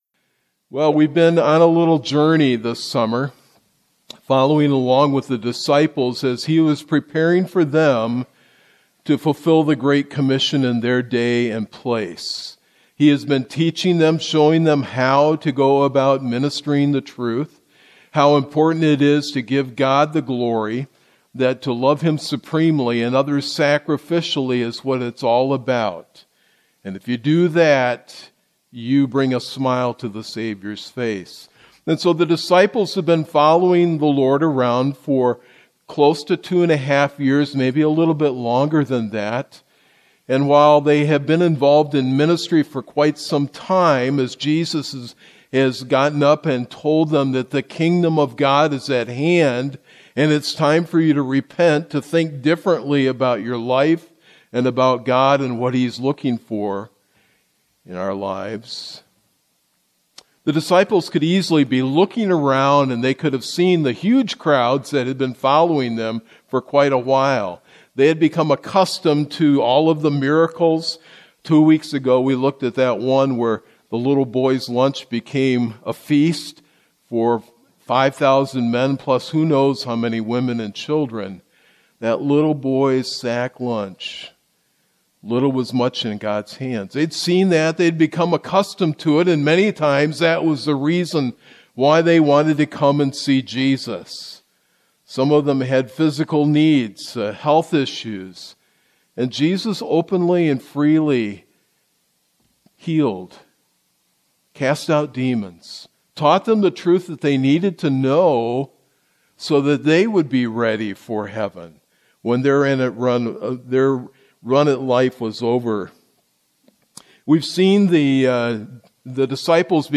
Sermons | Bethany Baptist Church
Guest Speaker